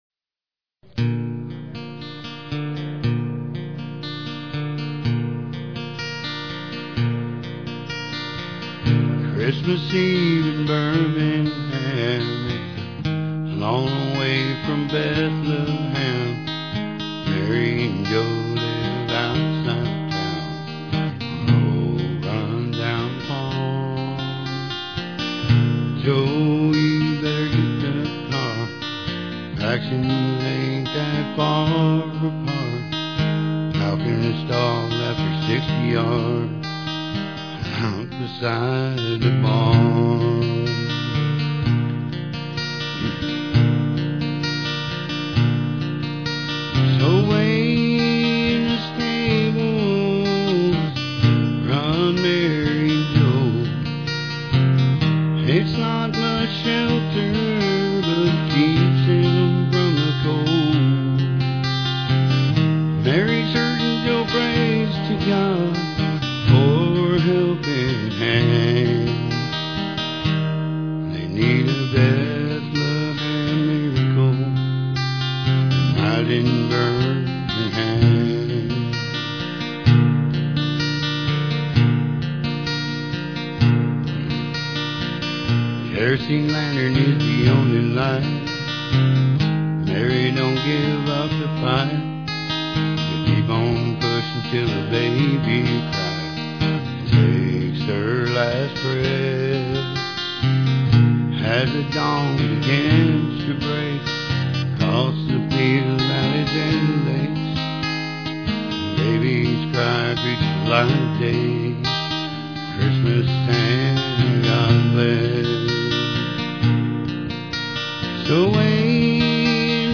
guitar and vocal solo